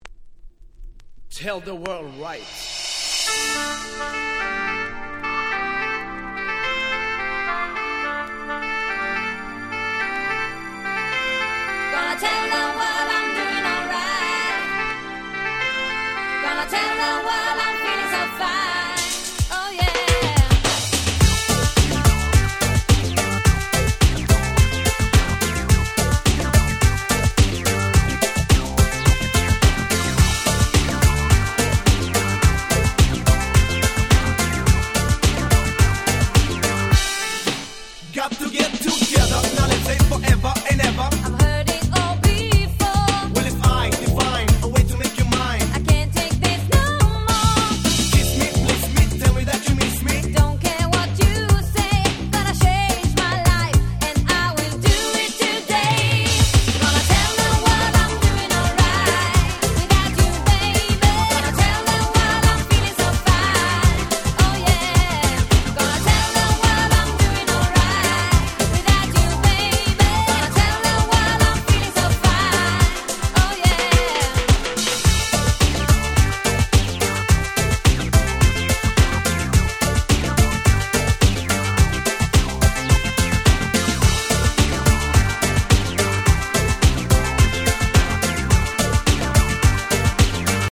この辺のRagga Pop物、キャッチーでやっぱり最高ですよね。
パンドラ キャッチー系 ラガポップ ヨゴレ系